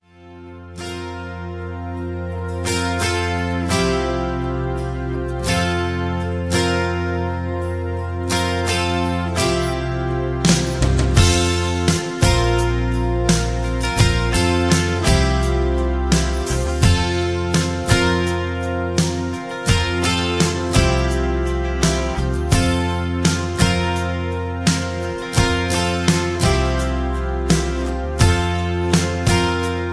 (Version-3, Key-F) Karaoke MP3 Backing Tracks
Just Plain & Simply "GREAT MUSIC" (No Lyrics).